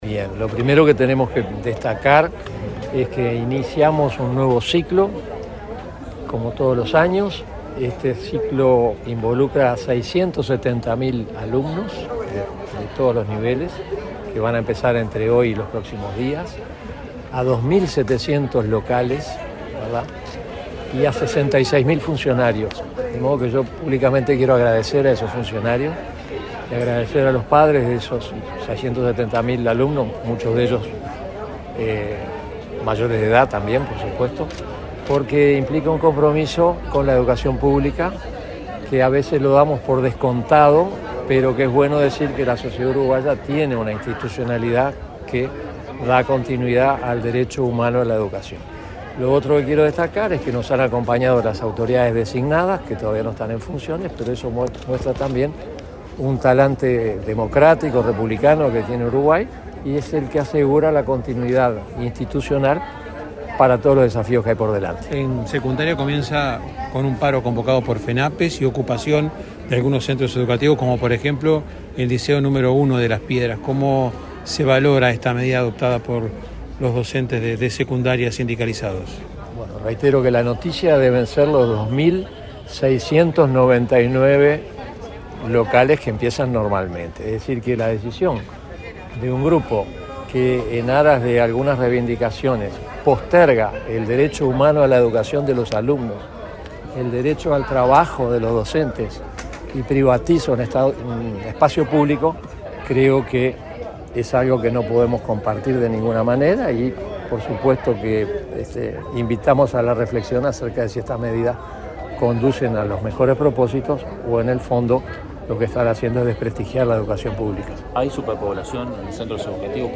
Declaraciones del presidente de la ANEP, Juan Gabito Zóboli
El presidente de la Administración Nacional de Educación Pública (ANEP), Juan Gabito Zóboli, dialogó con la prensa, durante la recorrida que realizó,